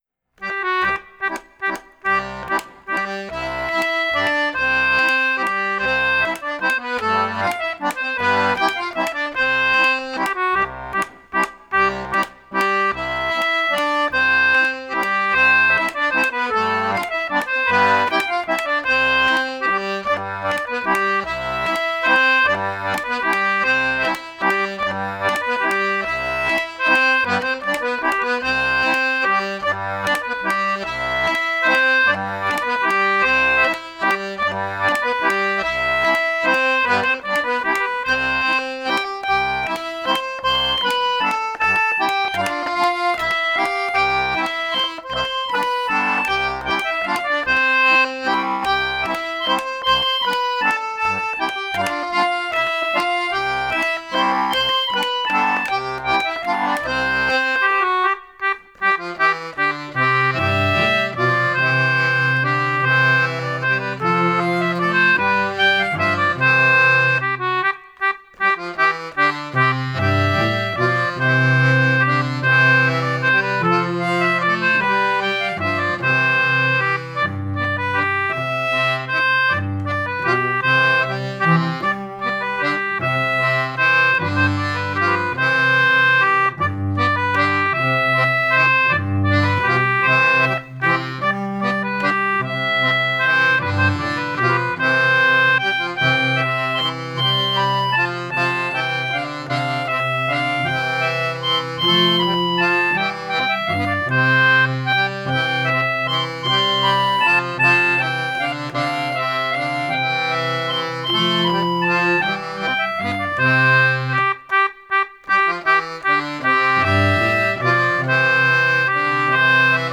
folk musicians and singers